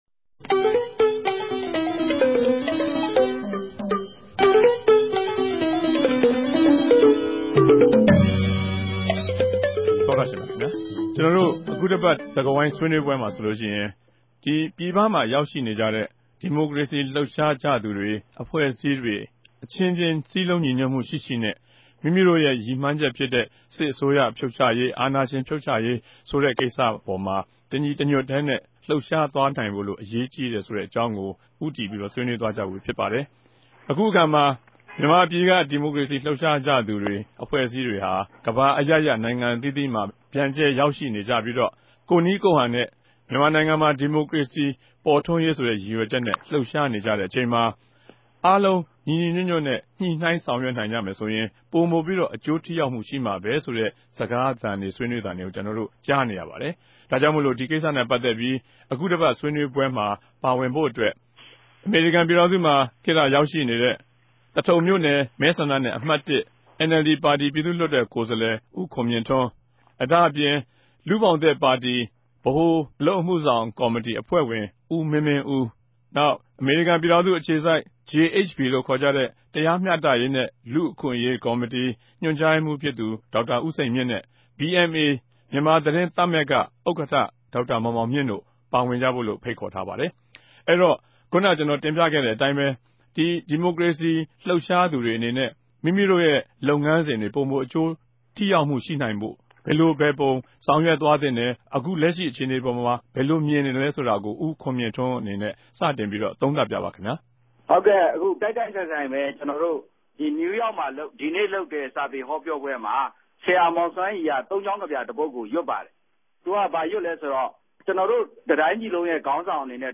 တနဂဿေိံြ စကားဝိုင်း။